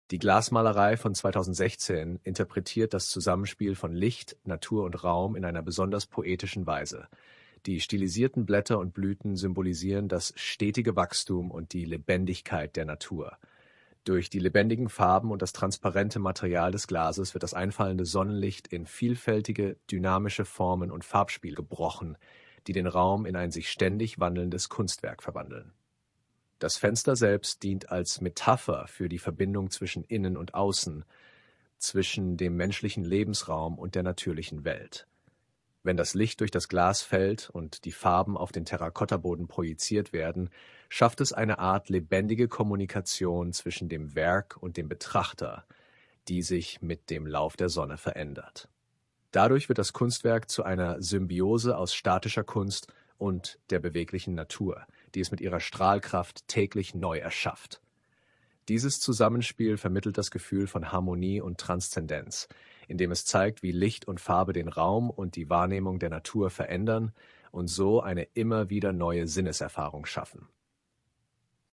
KI Interpretation